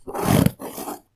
Wooden_Crate_Open.ogg